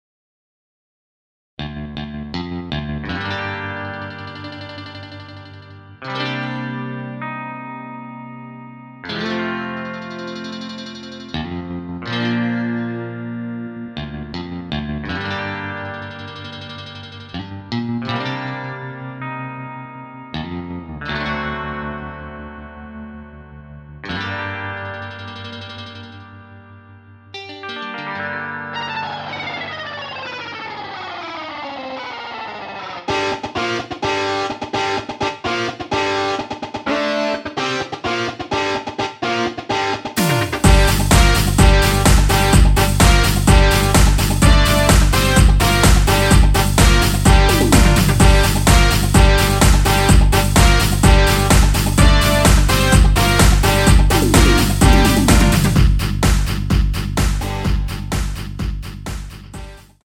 Gm
앞부분30초, 뒷부분30초씩 편집해서 올려 드리고 있습니다.
중간에 음이 끈어지고 다시 나오는 이유는